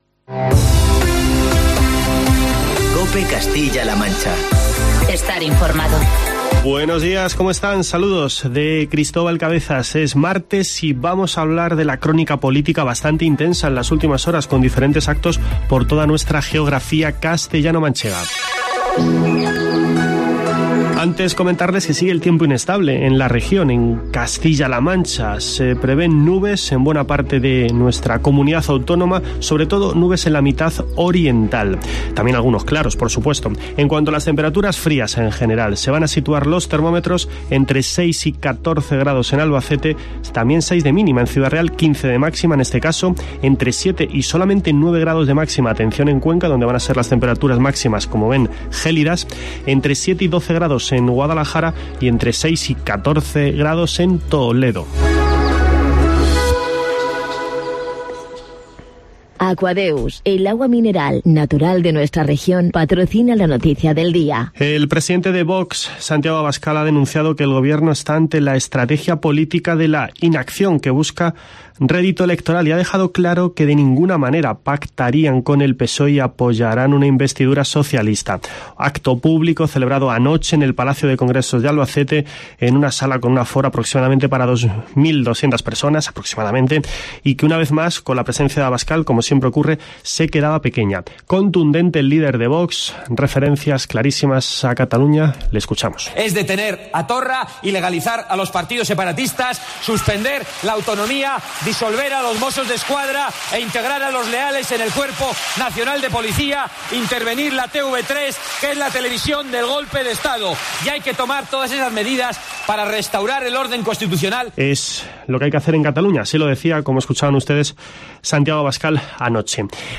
Escucha en la parte superior de esta noticia el informativo matinal de COPE Castilla-La Mancha de este martes, 22 de octubre.